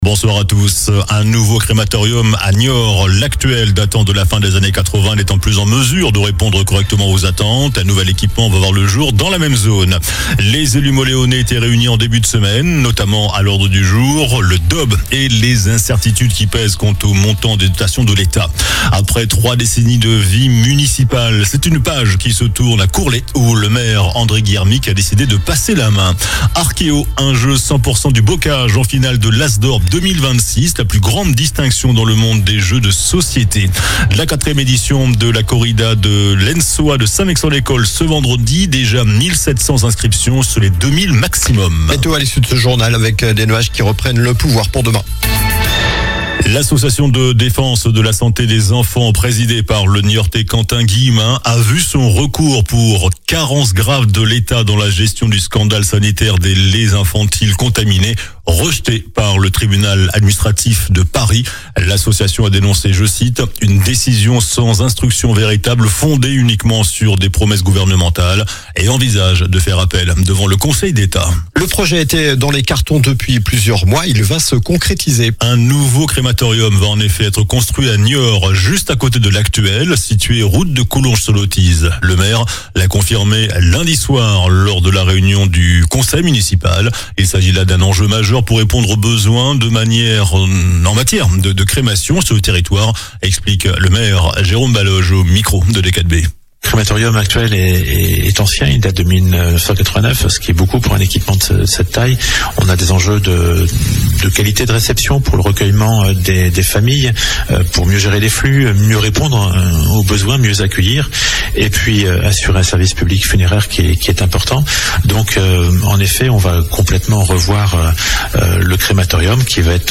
JOURNAL DU MERCREDI 28 JANVIER ( SOIR )